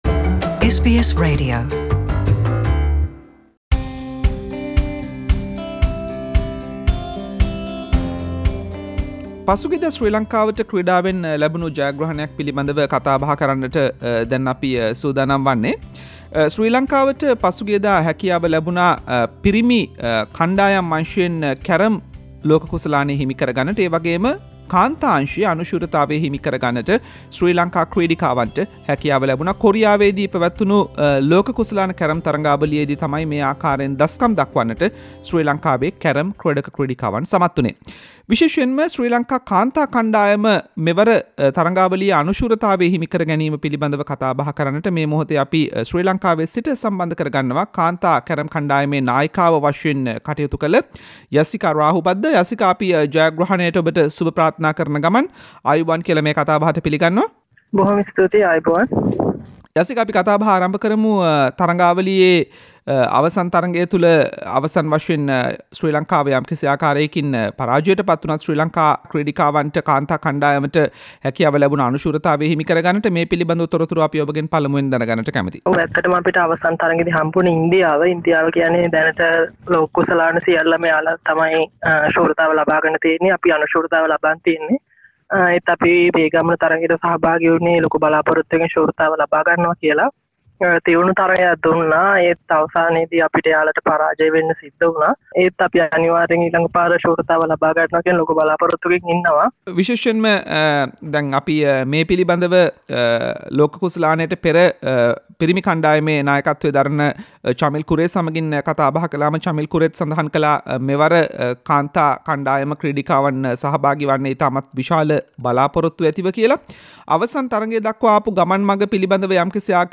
SBS සිංහල වැඩසටහන කල කතා බහක්.